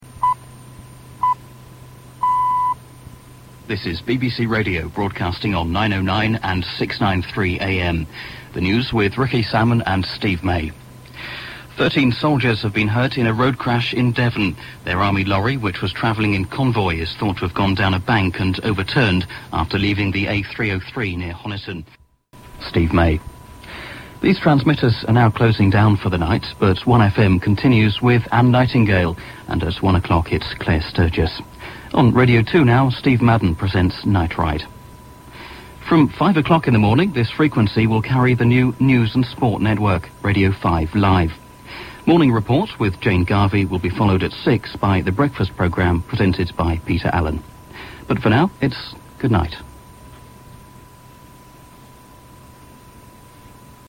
closing announcement